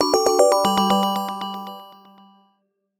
çoğunlukla eğlenceli ve hareketli zil seslerine sahip.